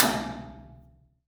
IMPACT_Metal_Tank_Stick_RR1_mono.wav